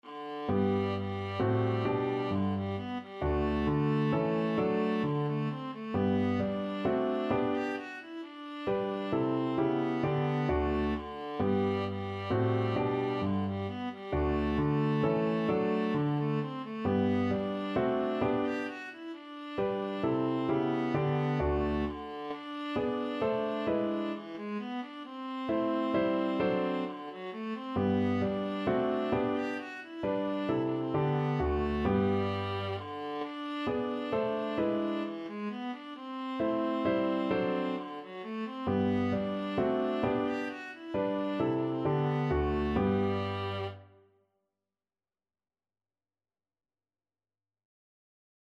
3/4 (View more 3/4 Music)
= 132 Allegro (View more music marked Allegro)
Classical (View more Classical Viola Music)